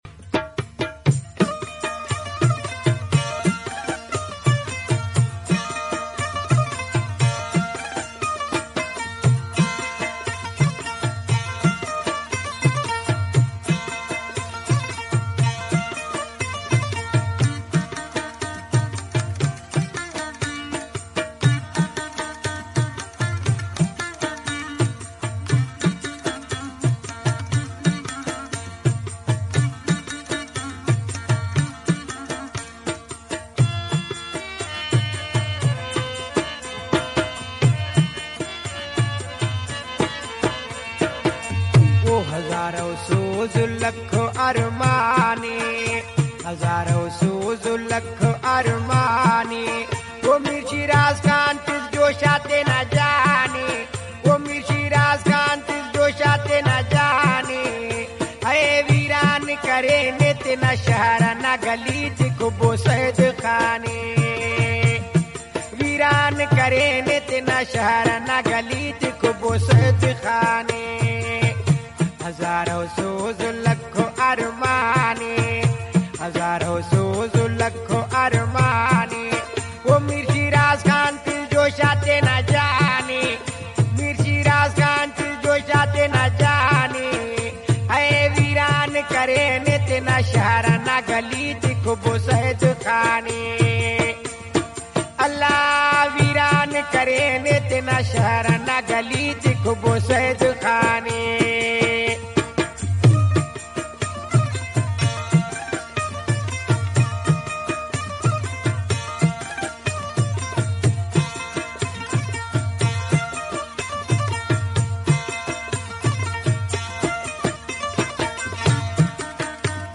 full brahvi song